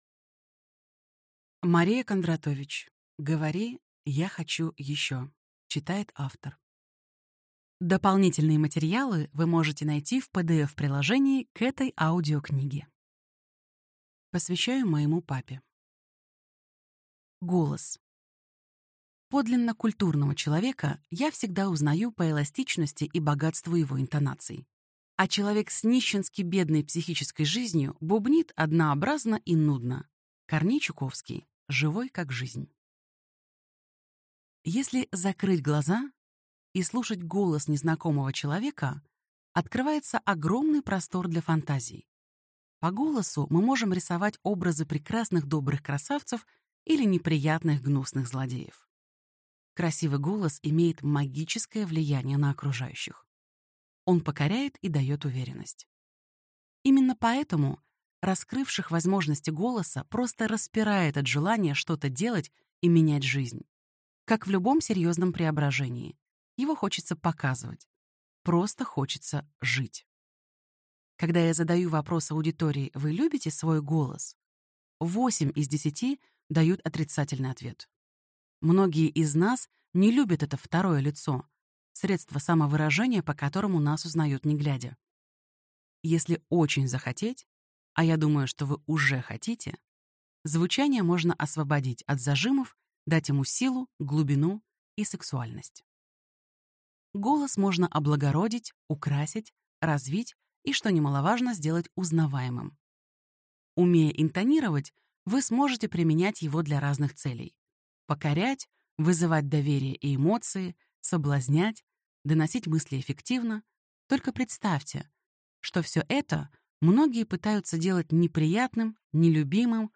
Аудиокнига Говори, я хочу еще! Как убеждать и достигать своих целей | Библиотека аудиокниг